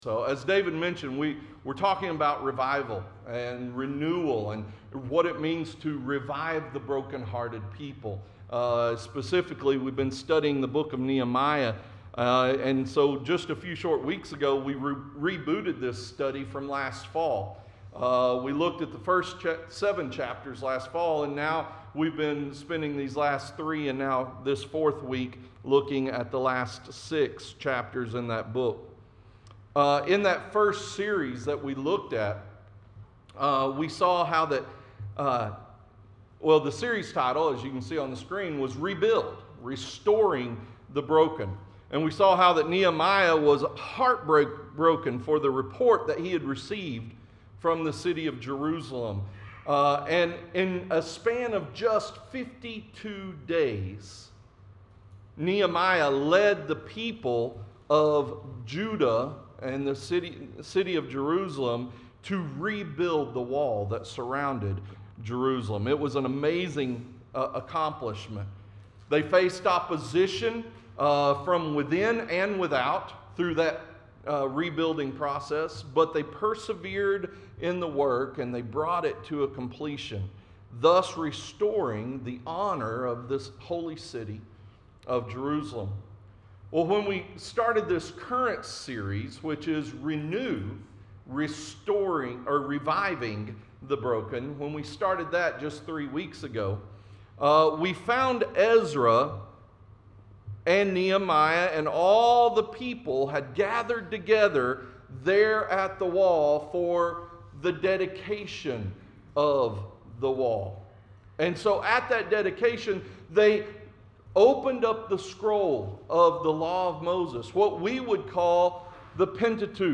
And we noted that renewal will result in commitment in our sermon last Sunday. Today—as we complete our study in Nehemiah—we will see that renewal requires consistency (i.e., doing something in the same way over a long period of time).